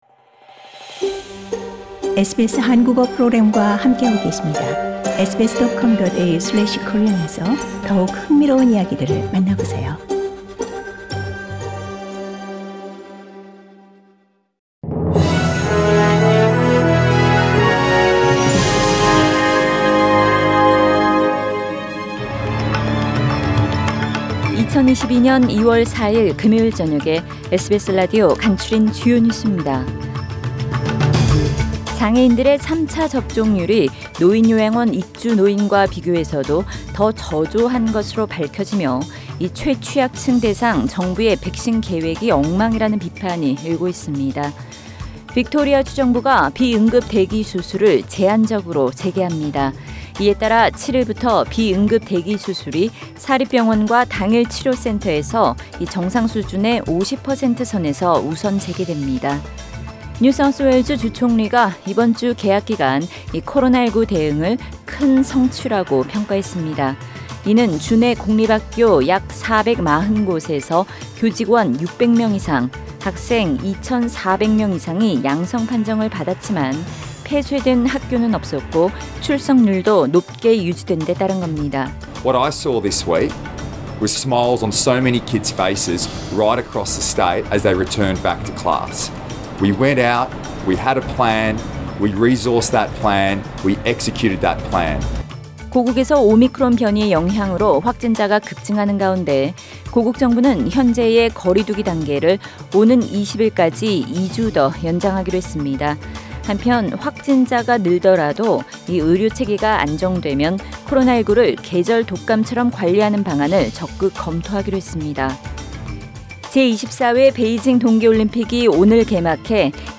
2022년 2월 4일 금요일 저녁의 SBS 뉴스 아우트라인입니다.